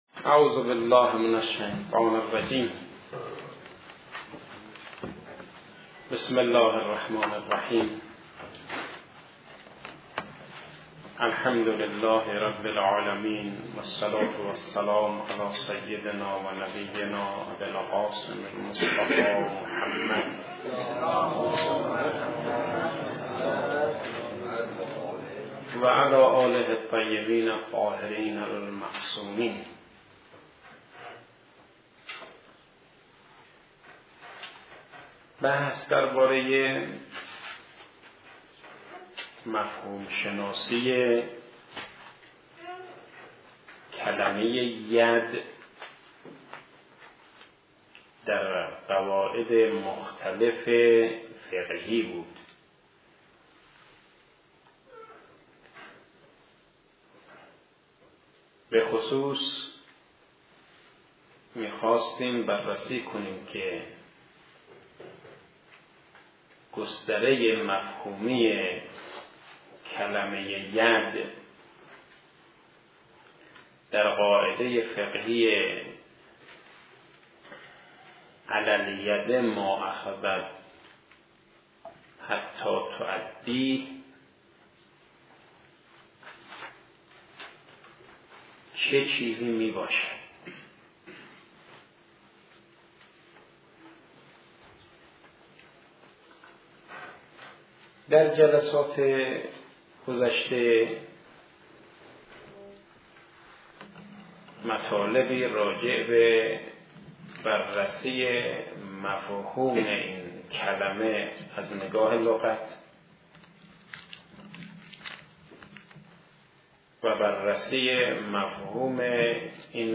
صوت دروس قواعد فقهی